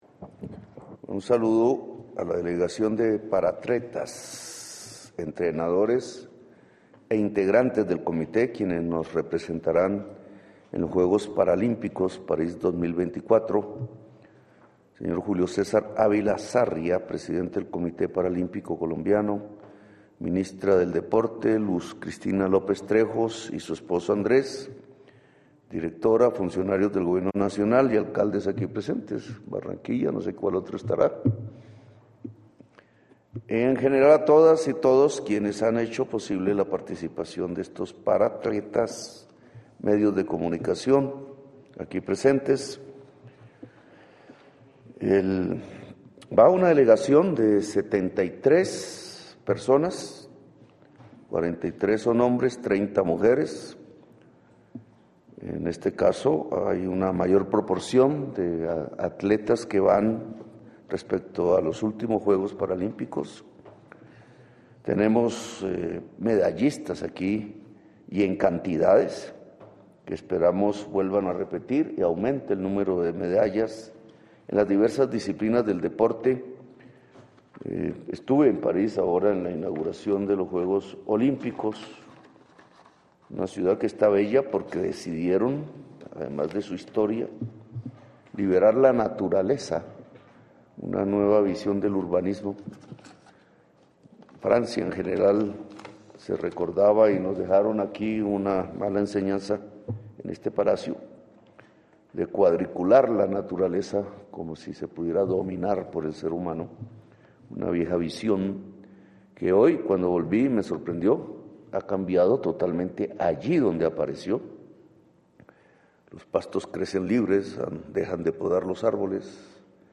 El jefe de Estado afirmó en la ceremonia en el Salón Gobelinos de la Casa de Nariño, que “cada vez el mundo se fija más en Colombia" y destacó el crecimiento progresivo en el último año del 8 por ciento respecto al año pasado “de turistas extranjeros que llegan aquí mejorando nuestras circunstancias de balanza de pagos.